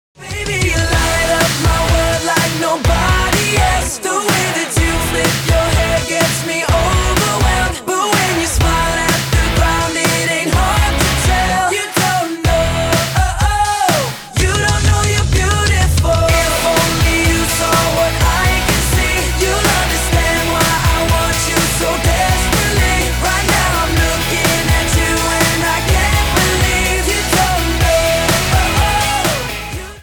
– Thể loại: “Âu Mỹ”